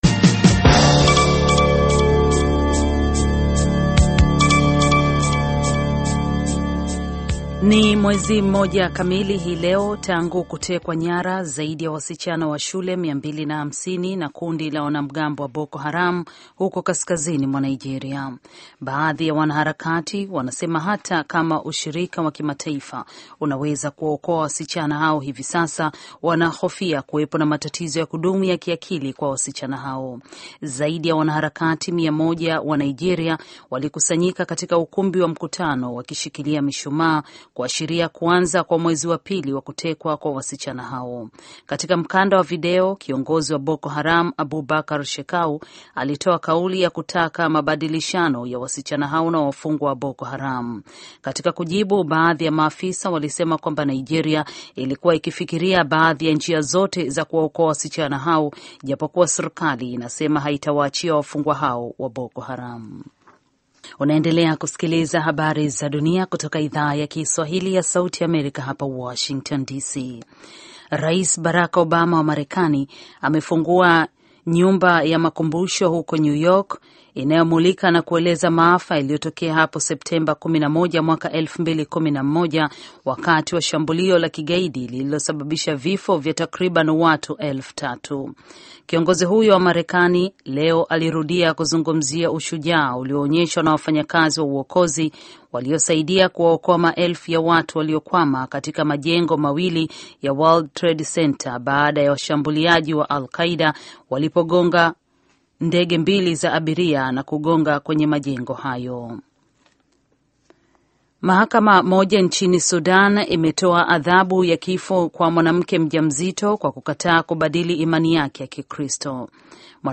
Taarifa ya Habari VOA Swahili - 4:25